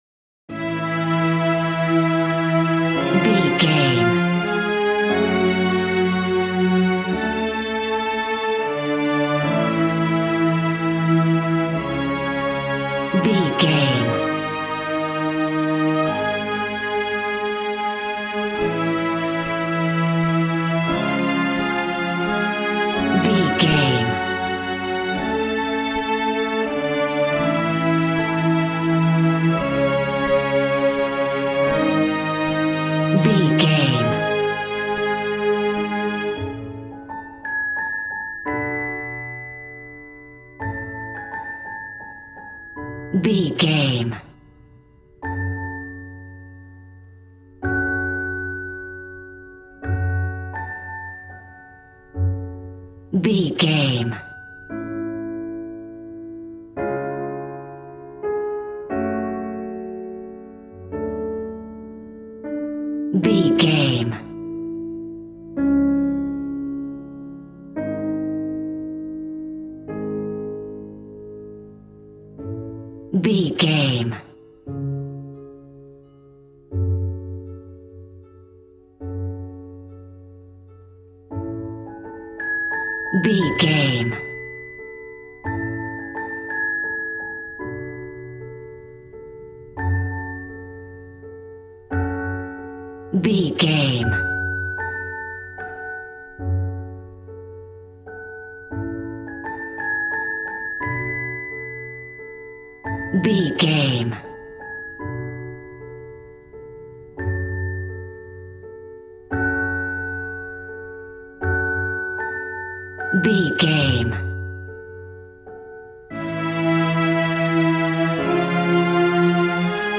Regal and romantic, a classy piece of classical music.
Aeolian/Minor
D♭
regal
strings
violin